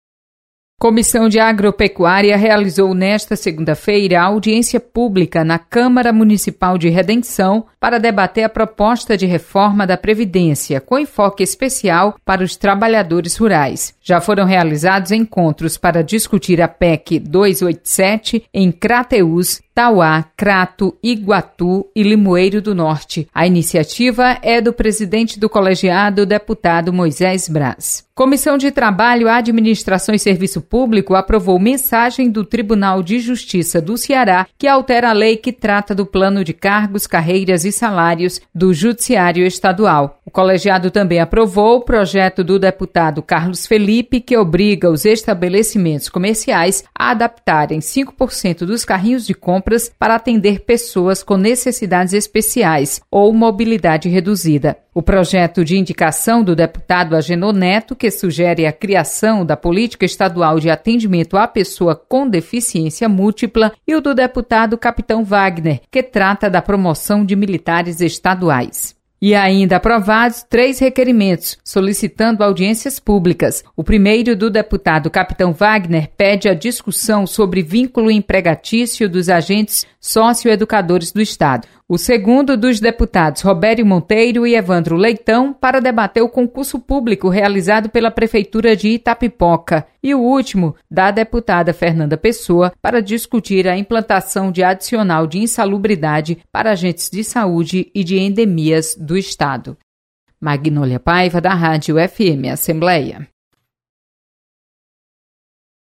Acompanhe o resumo das comissões técnicas permanentes da Assembleia Legislativa. Repórter